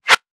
weapon_bullet_flyby_20.wav